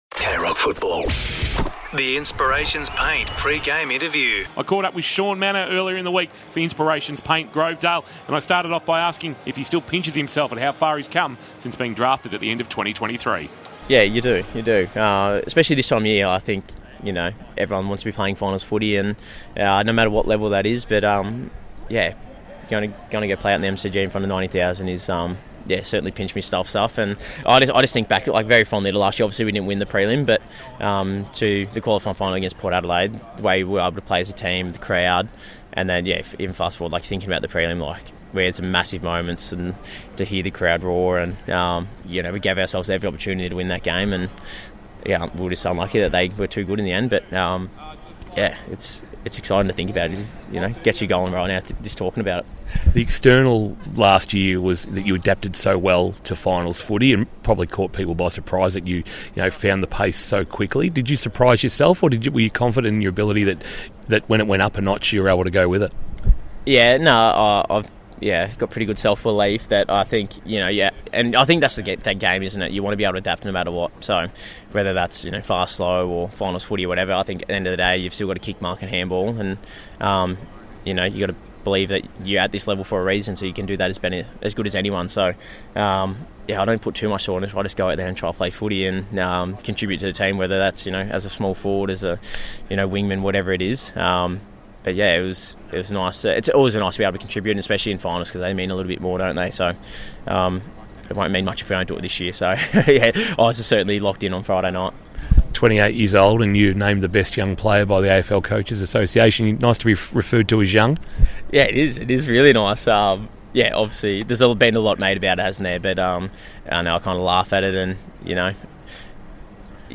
2025 - AFL - Qualifying Final - Geelong vs. Brisbane Lions - Pre-match interview: Shaun Mannagh (Geelong)